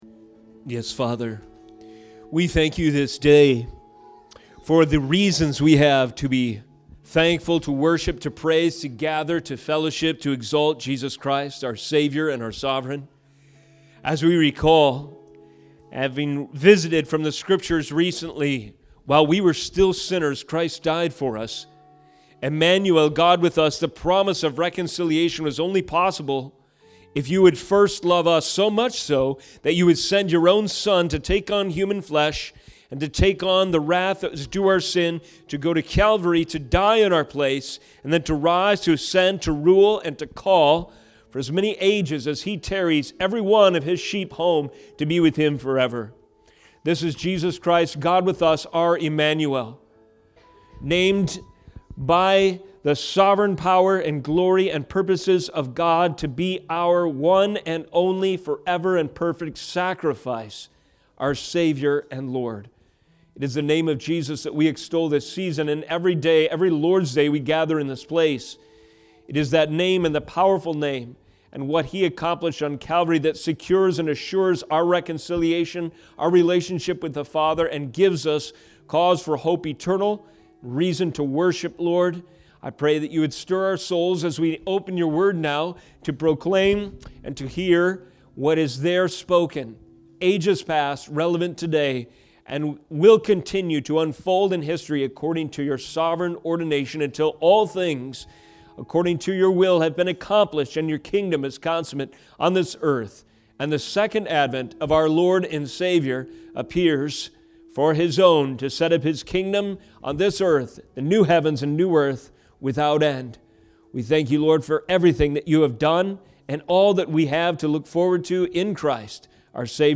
Reformed Theology. Sermons from Providence Community Church in Crosslake MN.